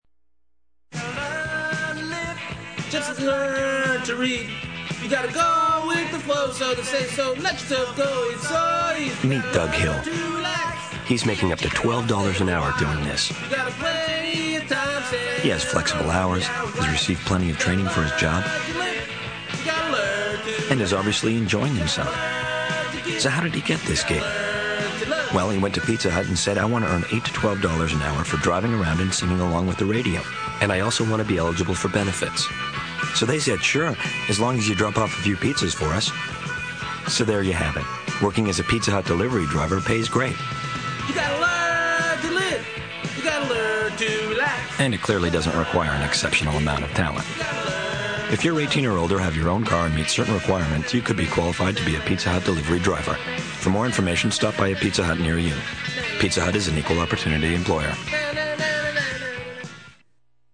7. Make the company or the job sound like fun by using humor – but make sure the humor really works, and that it serves the message rather than calling attention to itself. Here’s a great example used by Pizza Hut a few years back: